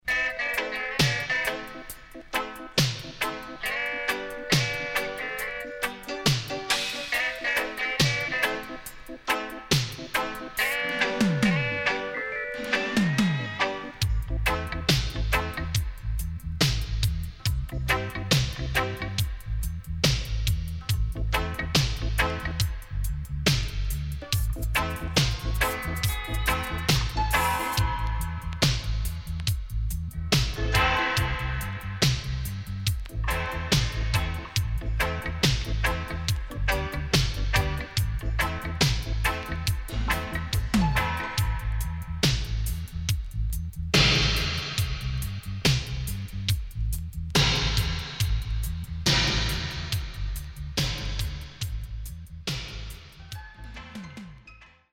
HOME > Back Order [DANCEHALL DISCO45]
SIDE A:少しチリノイズ入ります。